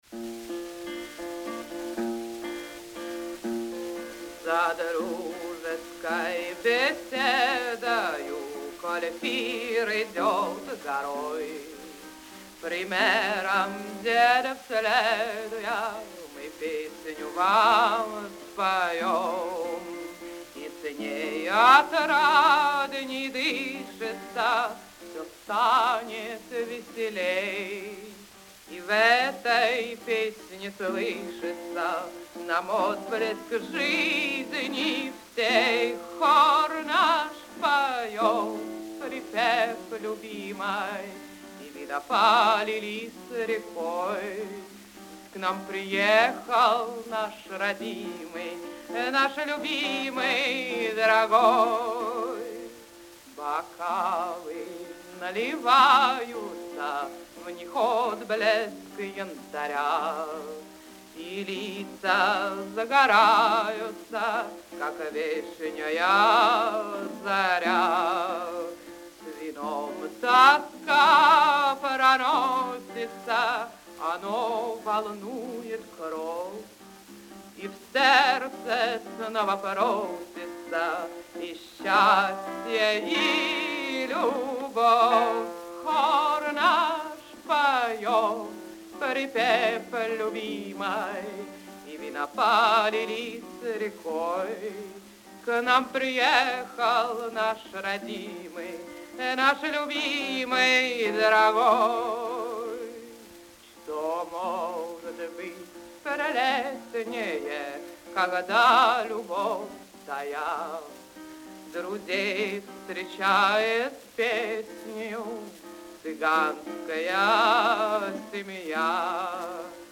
spiewa pry akomp. gitary - За дружеской беседой